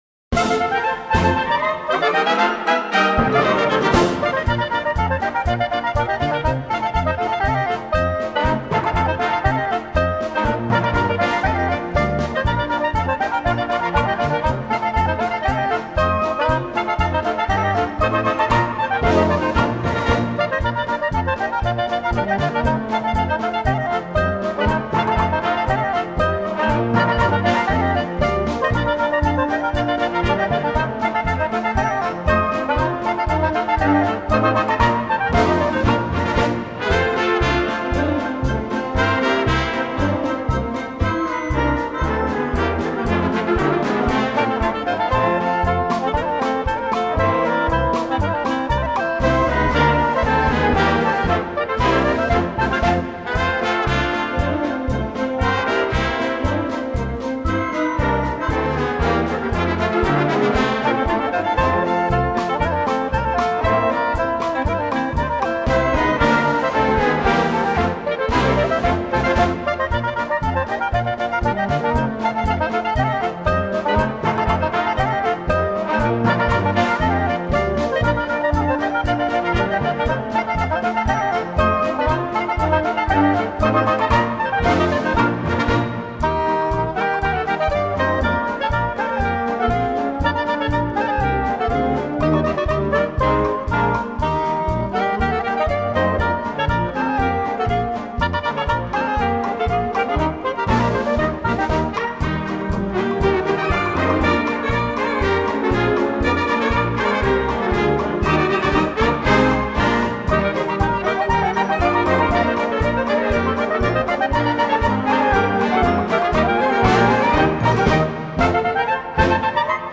Swiss Army Concert Band: Urnerbodä-Kafi. Swiss Folk Dance.